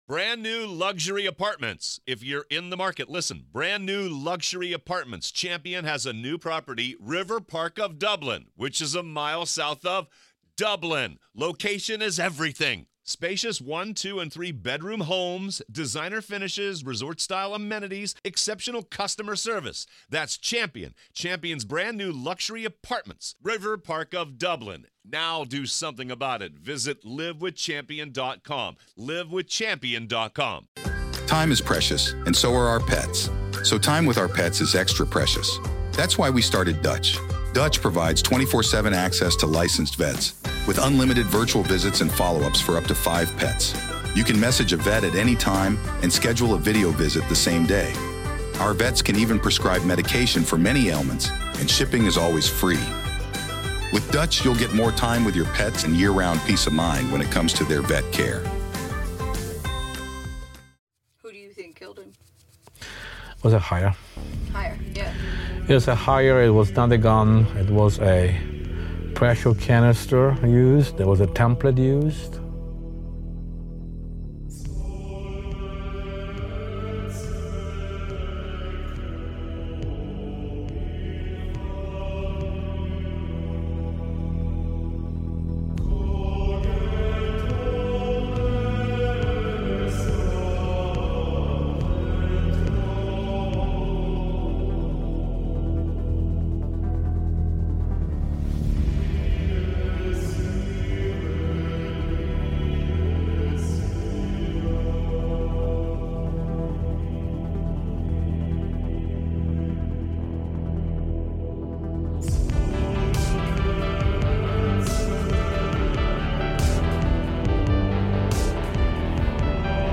We interview